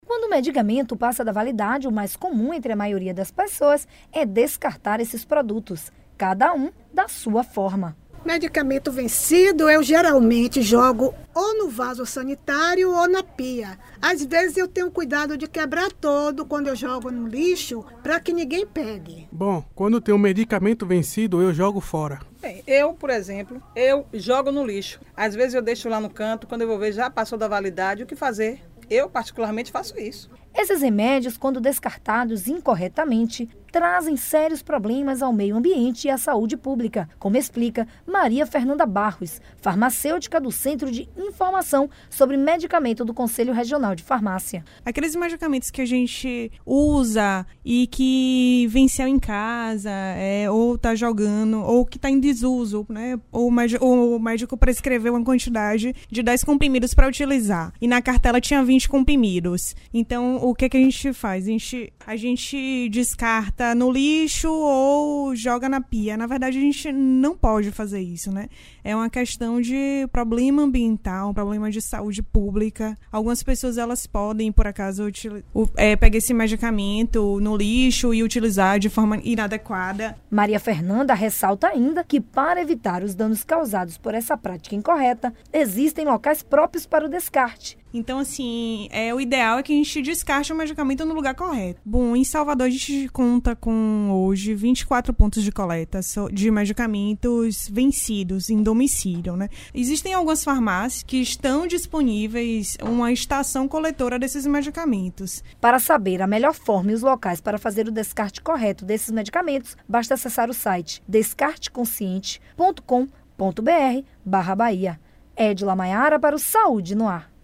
Abaixo, parte da entrevista